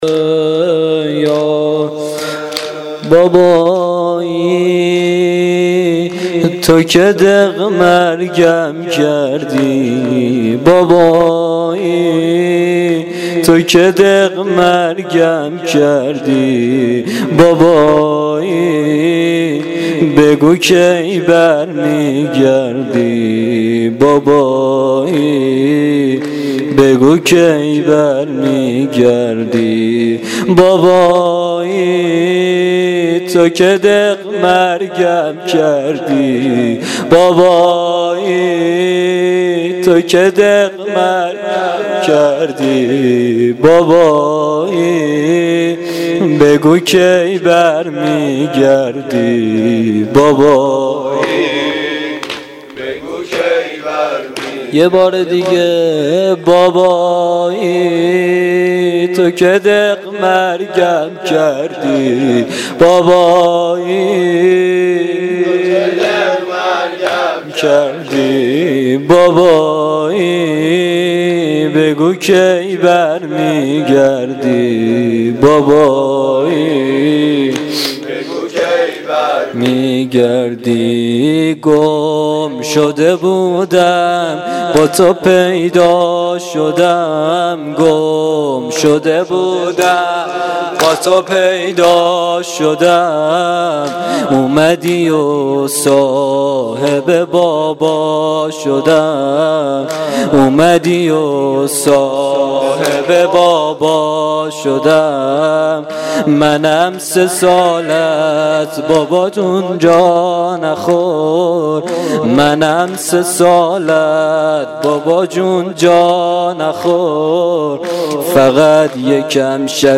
زمینه شب سوم محرم الحرام 1395